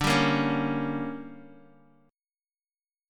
DM7sus4#5 chord